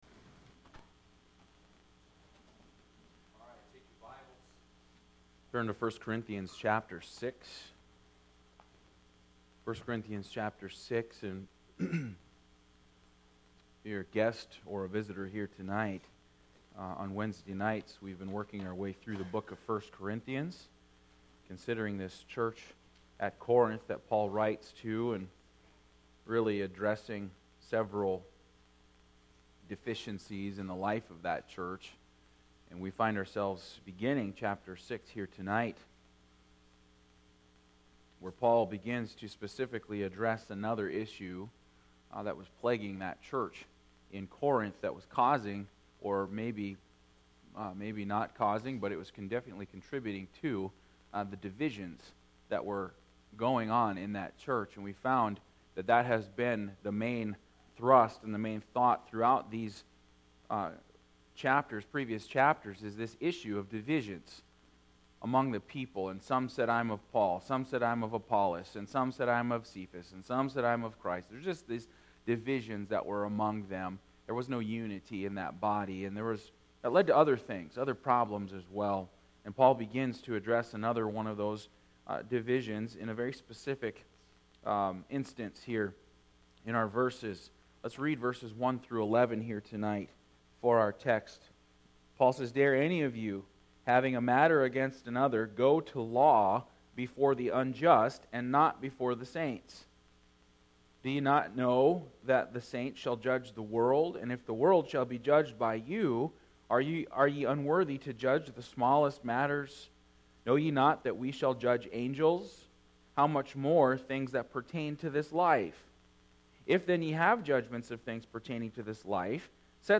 Passage: 1 Corinthians 6:5-11 Service Type: Wednesday Evening